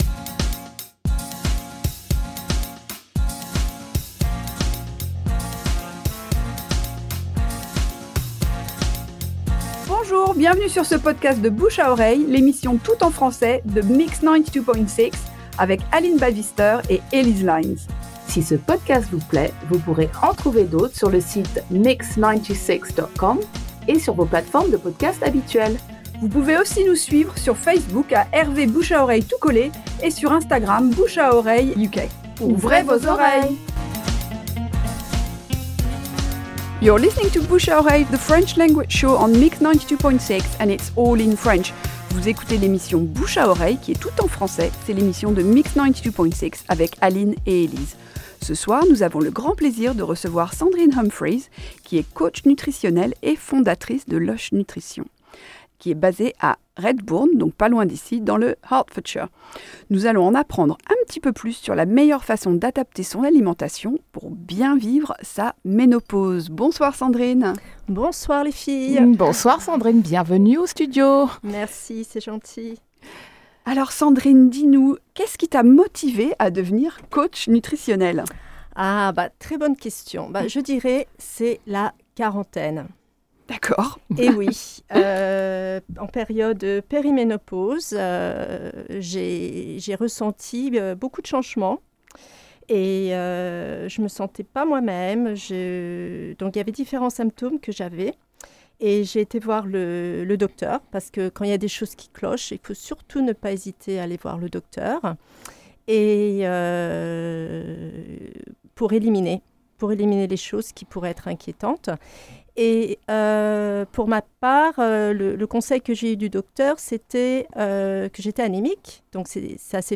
un entretien instructif et passionnant, en nous expliquant comment adopter une alimentation et une hygiène de vie adaptées à la période de (péri)-ménopause.